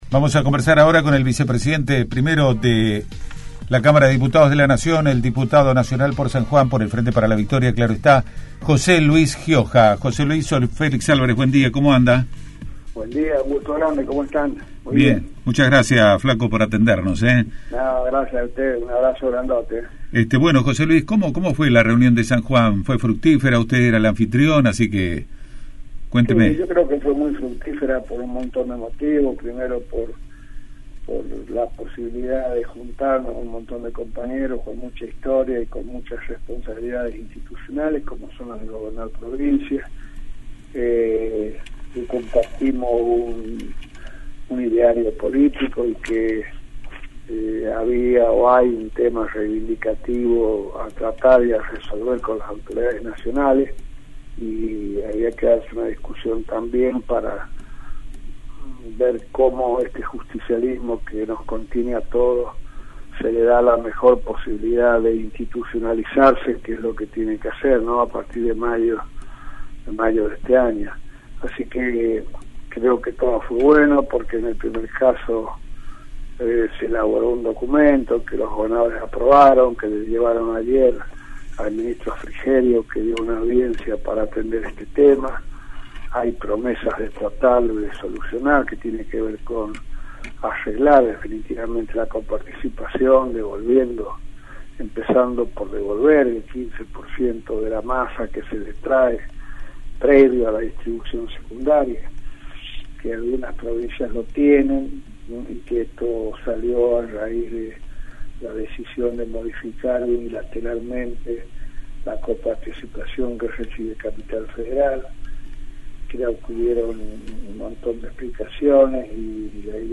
Además, comentó los caminos del peronismo para los proximos años. Escucha la entrevista: